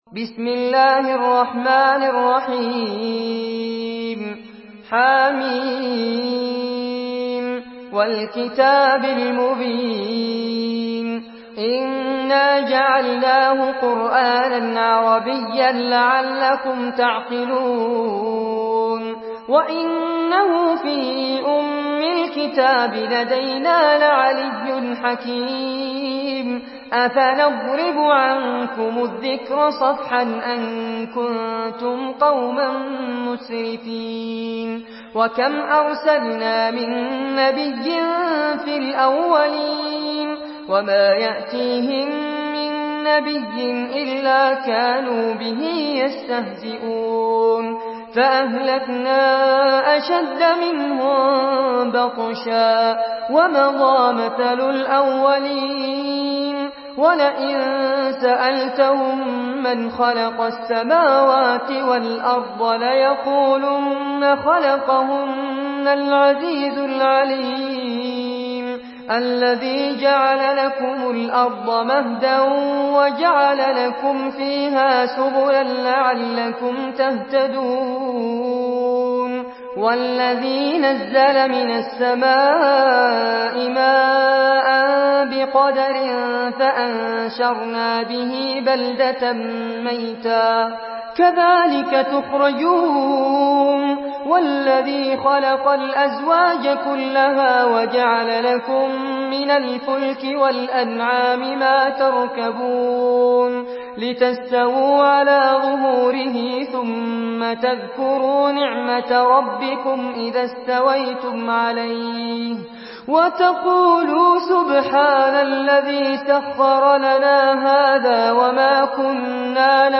Surah Az-Zukhruf MP3 in the Voice of Fares Abbad in Hafs Narration
Murattal Hafs An Asim